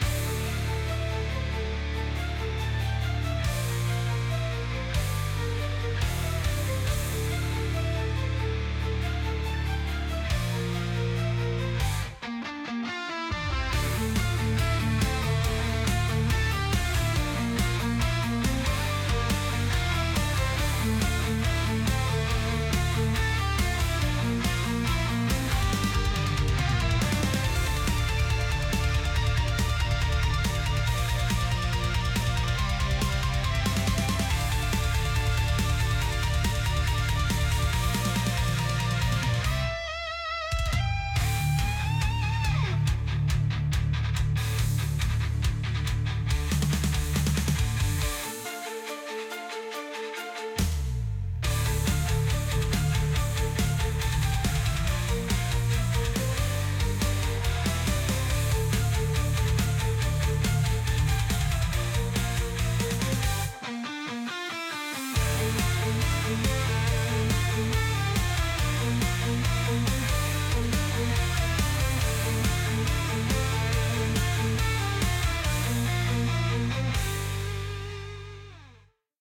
ambience